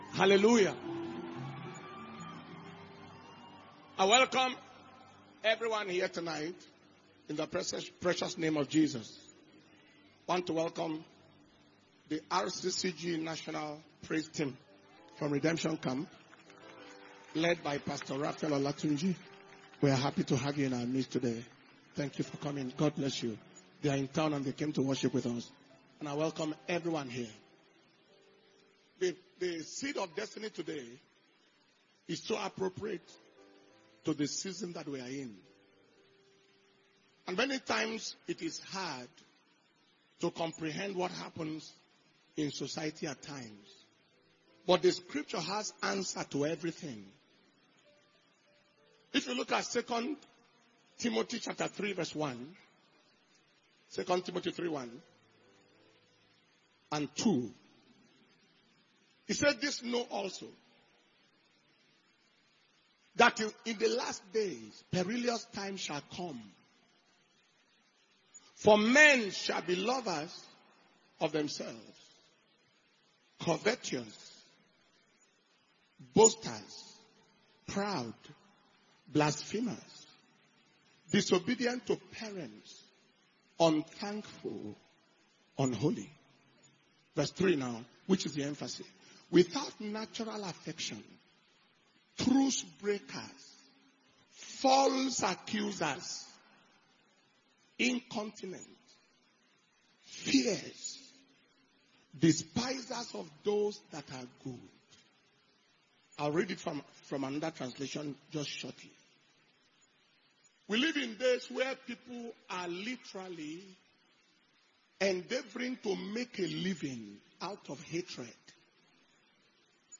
Power Communion Service -April 17th
Message